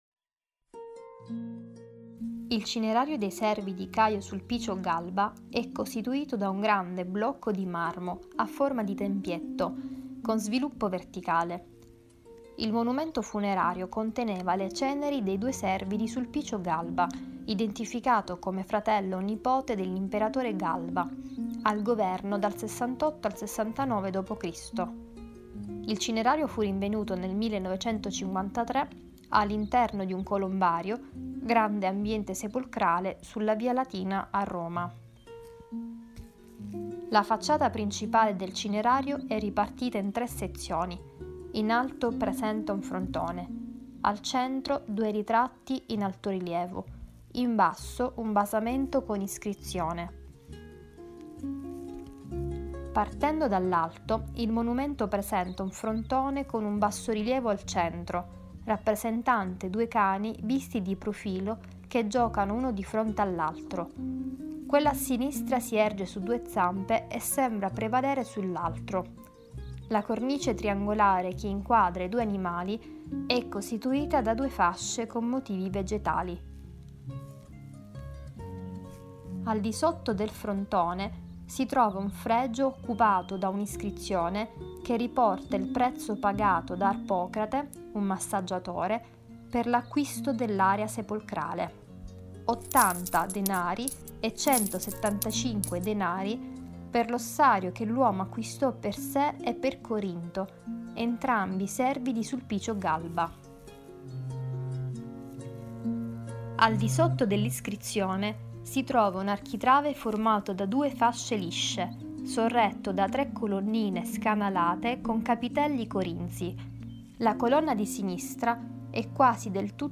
The reading is accompanied by musical fragments and sound references that suggest details of the story, stimulating the imagination and promoting knowledge of the work through immersive perception.
Audio description in Italian (.mp3)